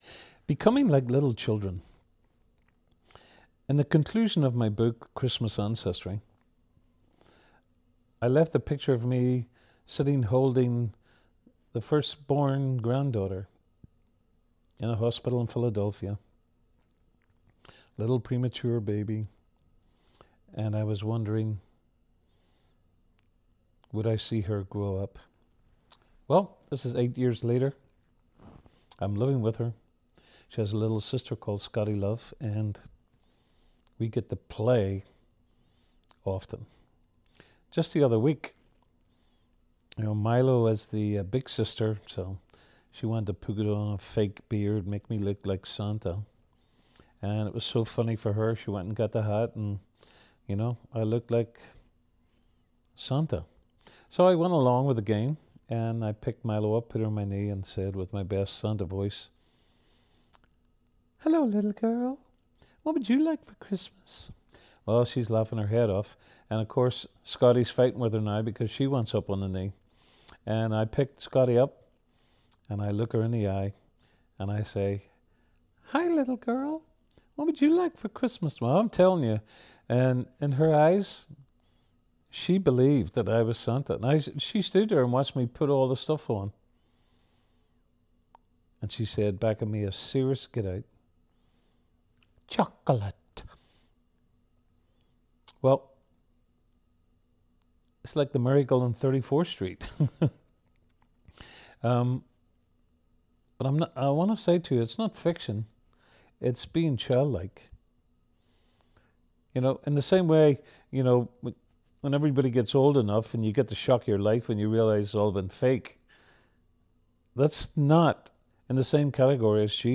A Christmas devotional